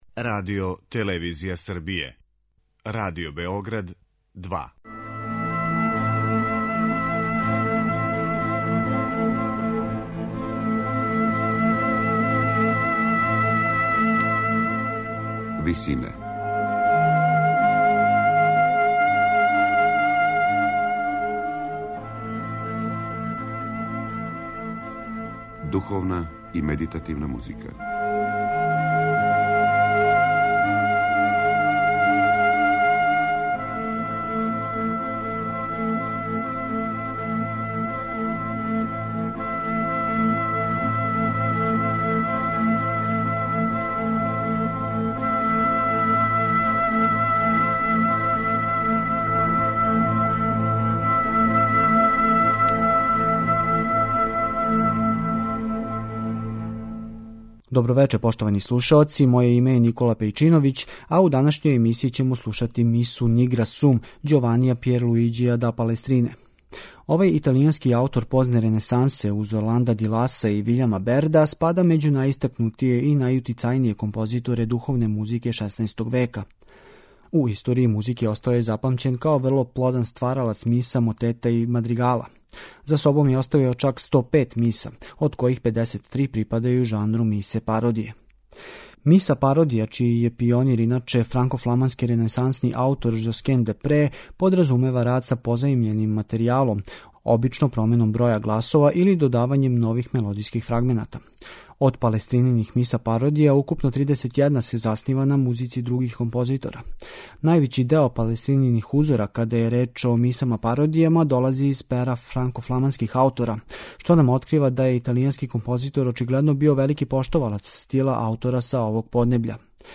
Емитоваћемо мису 'Nigra sum', италијанског ренесансног ствараоца Ђованија Пјерлуиђија да Палестрине.
На крају програма, у ВИСИНАМА представљамо медитативне и духовне композиције аутора свих конфесија и епоха.